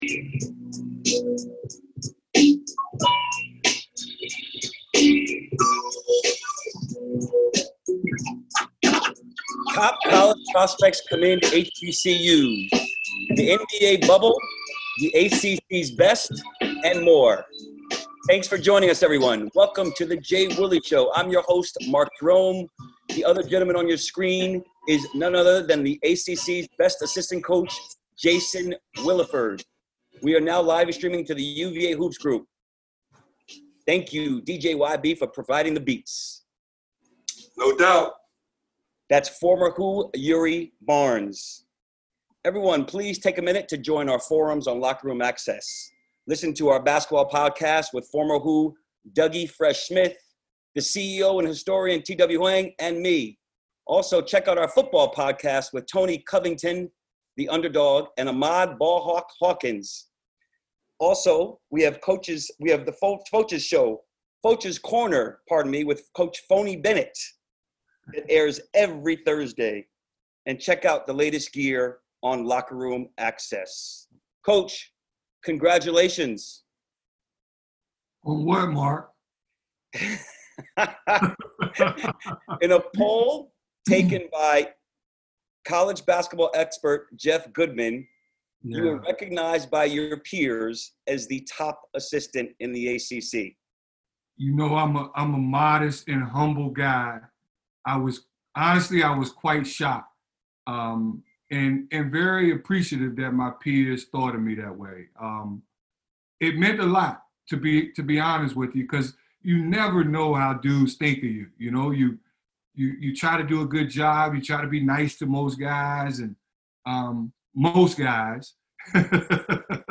This week, we were live on Facebook UVA Hoops Group.